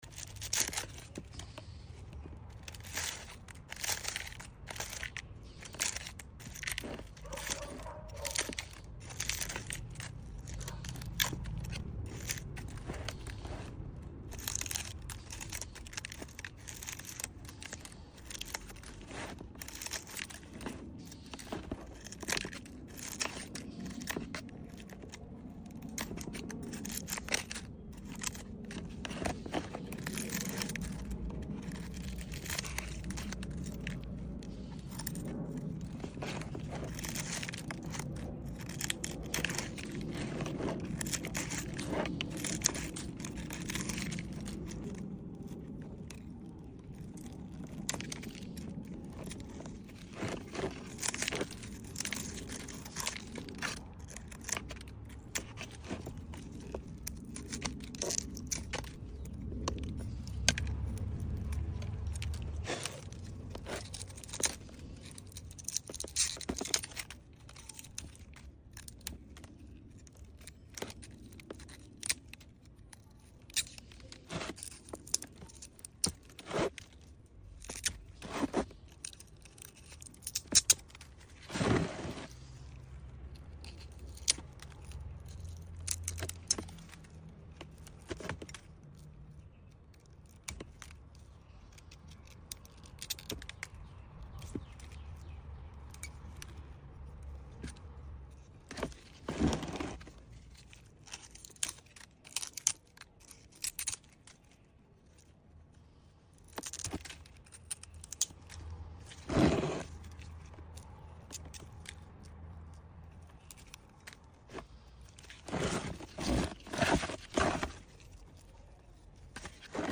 Settle in for an extended ASMR session as I peel away layers of dry succulent leaves. Enjoy every satisfying snap and crisp sound for pure relaxation and plant care bliss.